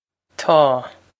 Taw
This is an approximate phonetic pronunciation of the phrase.